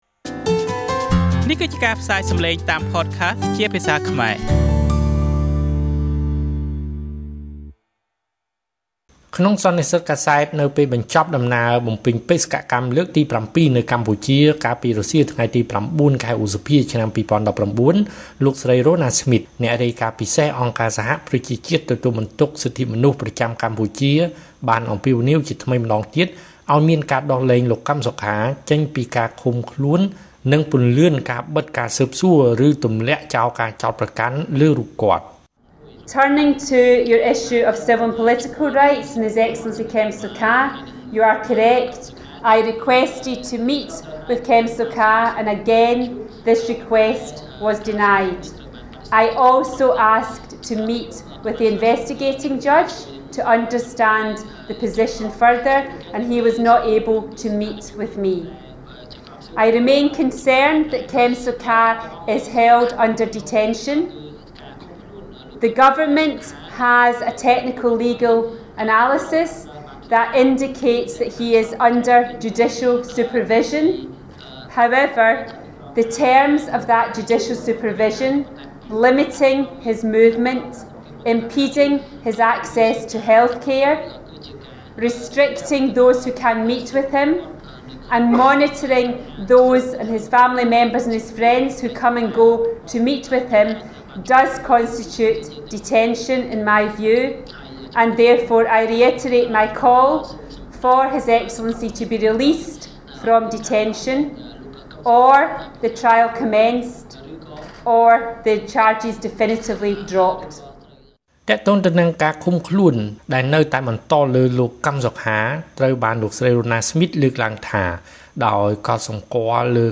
ក្នុងសន្និសីទកាសែត នៅពេលបញ្ចប់ដំណើរបំពេញបេសកកម្ម លើកទី៧ នៅកម្ពុជា កាលពីរសៀលថ្ងៃទី០៩ ខែឧសភា ឆ្នាំ២០១៩ លោកស្រី រ៉ូណា ស្មីត អ្នករាយការណ៍ពិសេសអង្គការសហប្រជាជាតិ ទទួលបន្ទុកសិទ្ធិមនុស្ស ប្រចាំកម្ពុជា បានអំពាវនាវជាថ្មីម្តងទៀត ឲ្យមានការដោះលែងលោកកឹមសុខា ចេញពីការឃុំខ្លួន និងពន្លឿនការបិទការស៊ើបសួរ ឬទម្លាក់ការចោទប្រកាន់លើរូបគាត់។